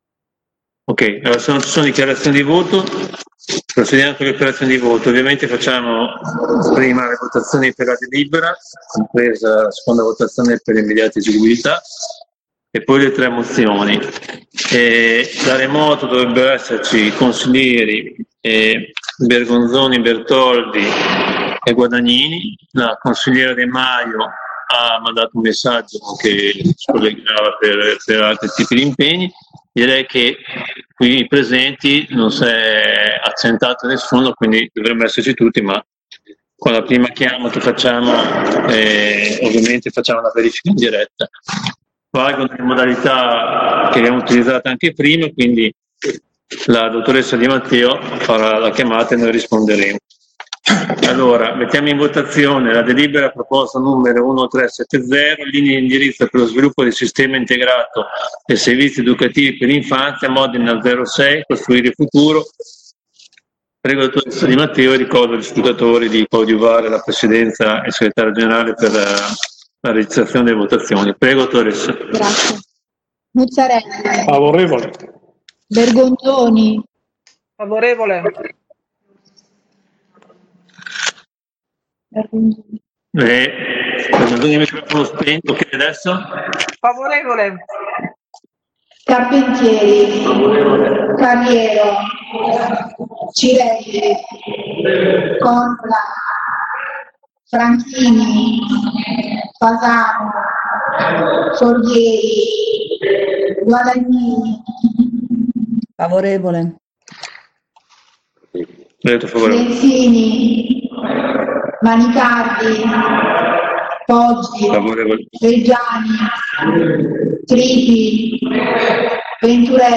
Fabio Poggi — Sito Audio Consiglio Comunale